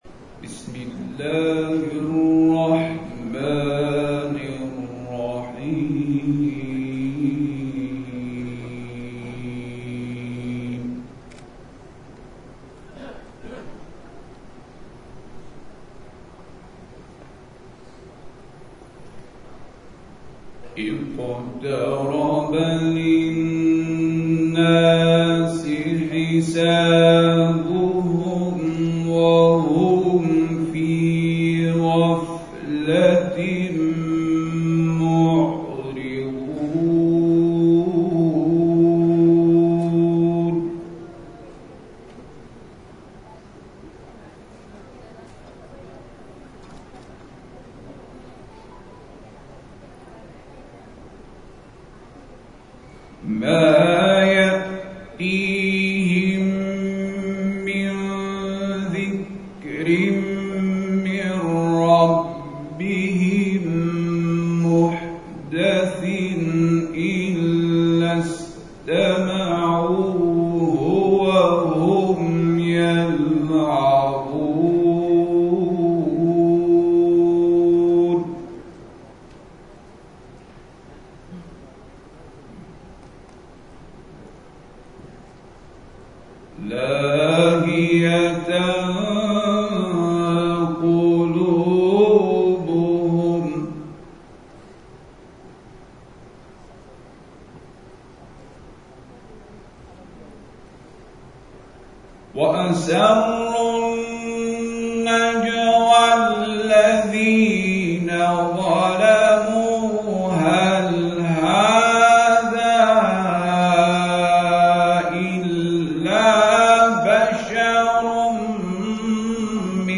سومین روز مسابقات بین‌المللی قرآن مالزی